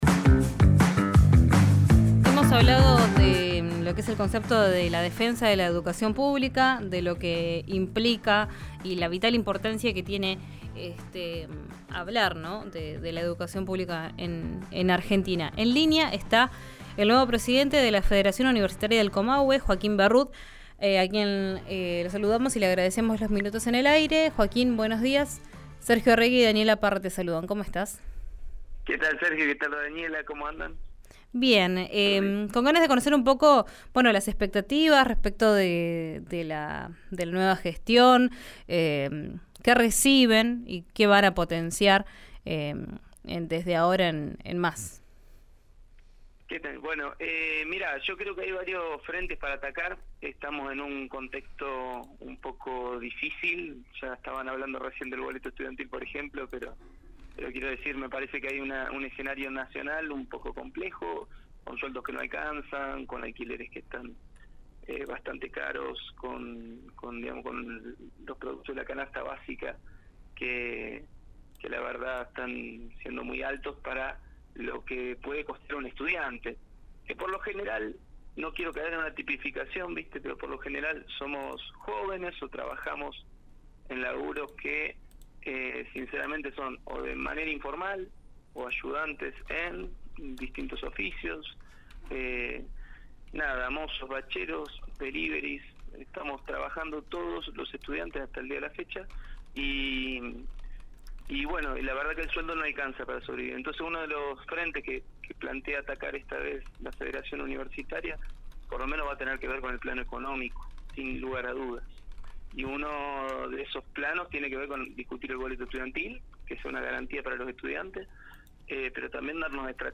Durante una entrevista en Ya Es Tiempo de RÍO NEGRO RADIO